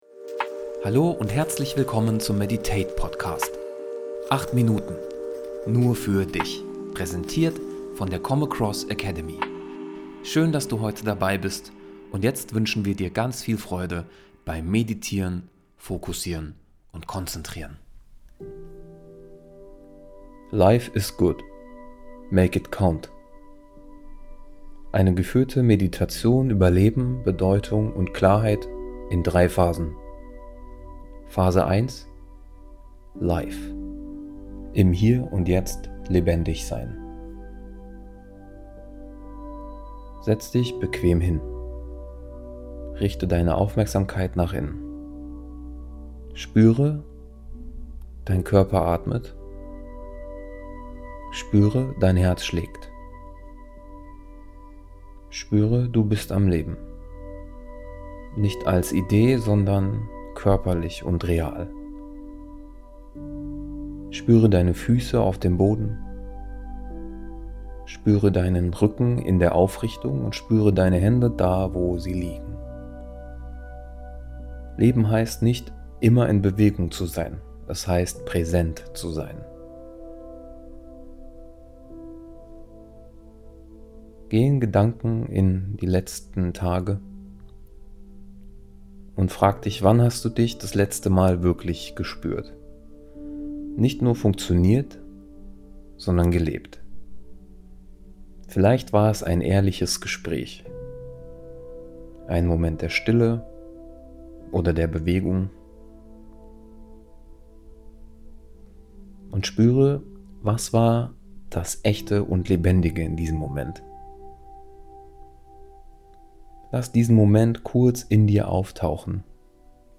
Life is good. Make it count. [Meditation] ~ Medit8 Podcast